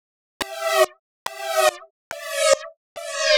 Index of /musicradar/uk-garage-samples/142bpm Lines n Loops/Synths
GA_SacherPad142E-01.wav